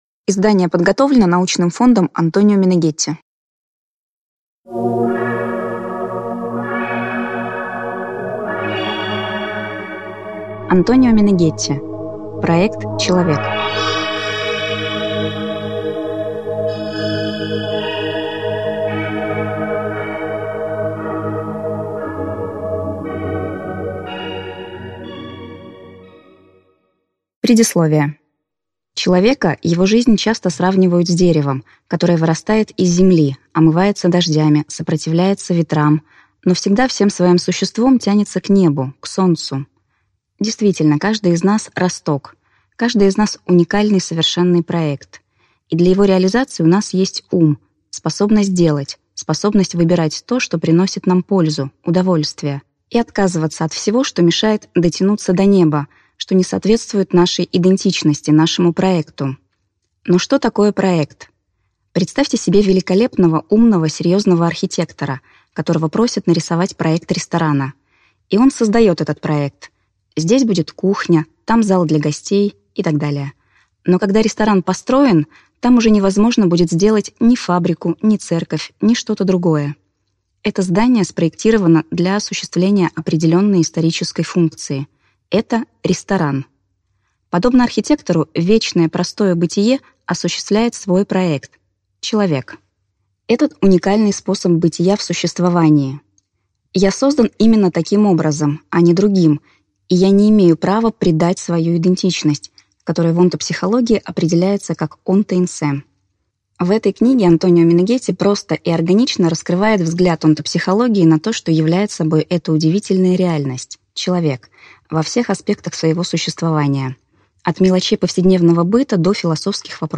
Аудиокнига Ум человека. Принцип действия | Библиотека аудиокниг